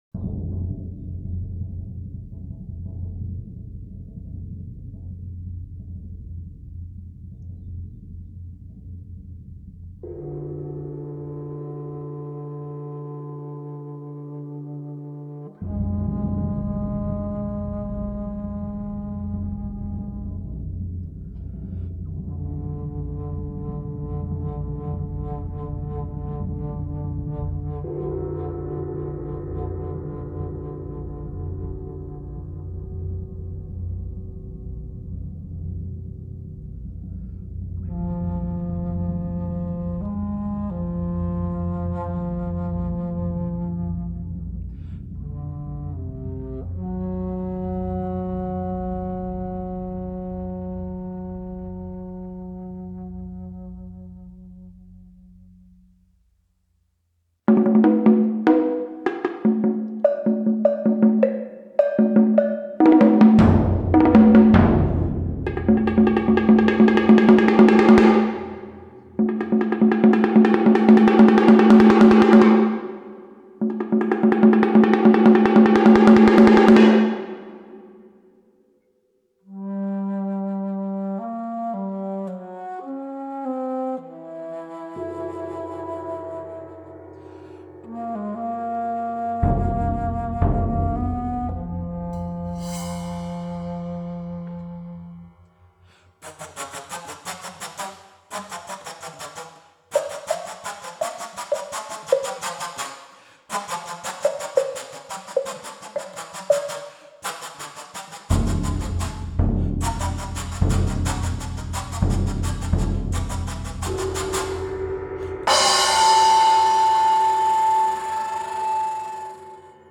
Fejelé para flauta y percusión